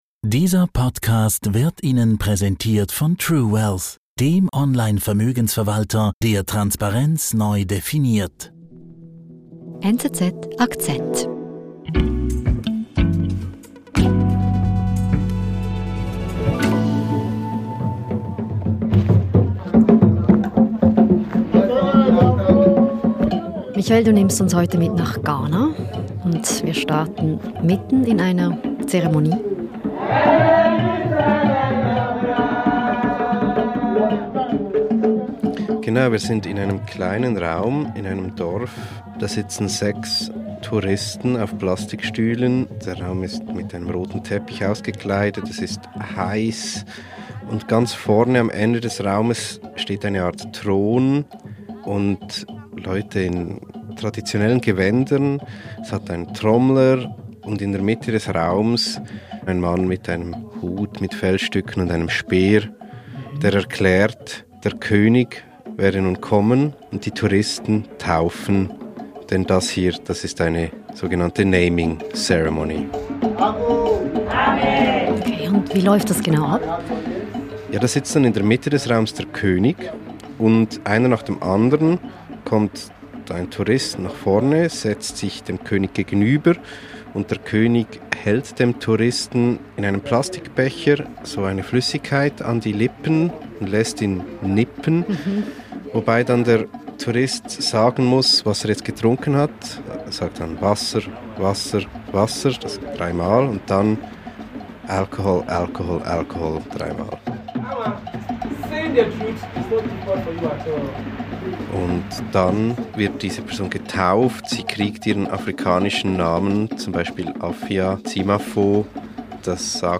Täglich erzählen NZZ-Korrespondentinnen und Redaktoren, was sie bewegt: Geschichten aus der ganzen Welt inklusive fundierter Analyse aus dem Hause NZZ, in rund 15 Minuten erzählt.